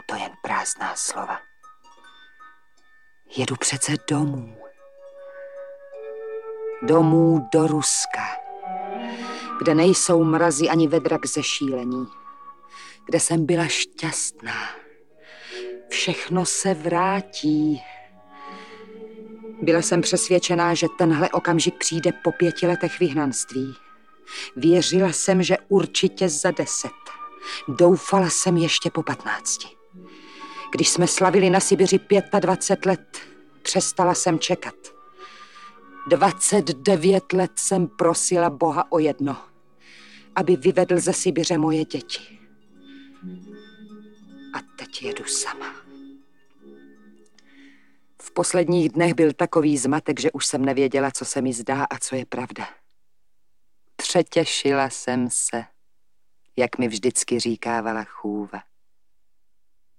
Audiobook
Read: Věra Galatíková